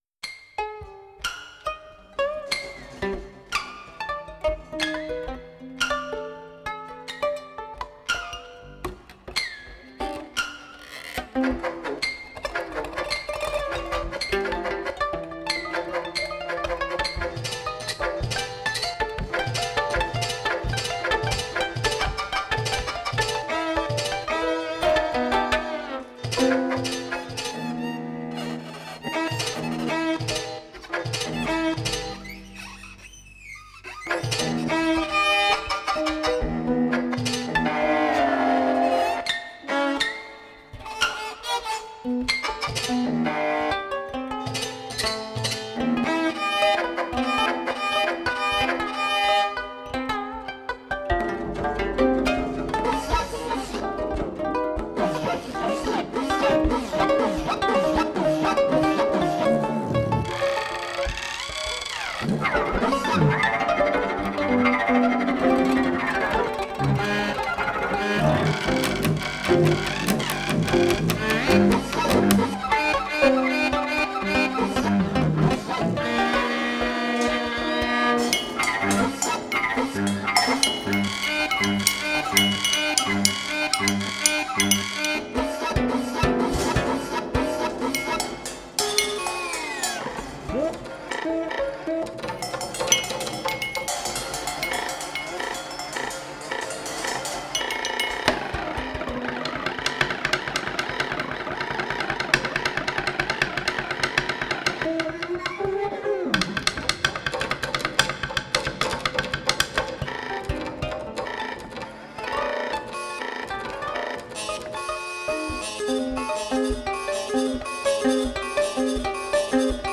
improvised music for strings